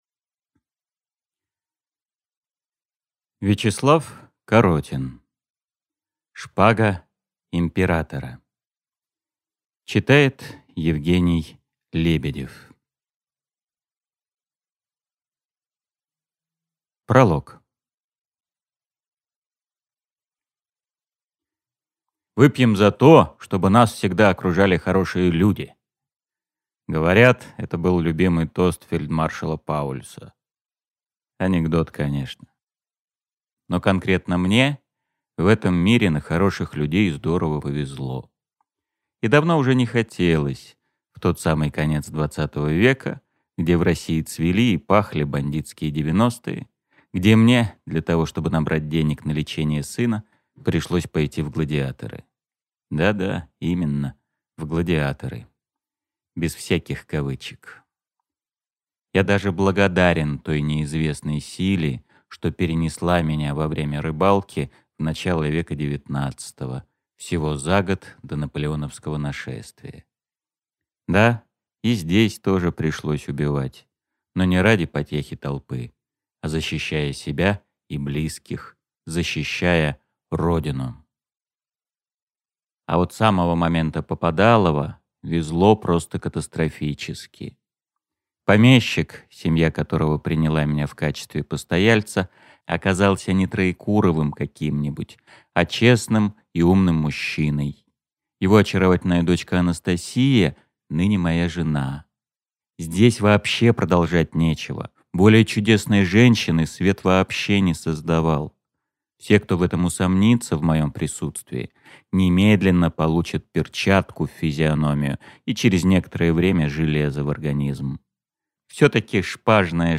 Аудиокнига Шпага императора | Библиотека аудиокниг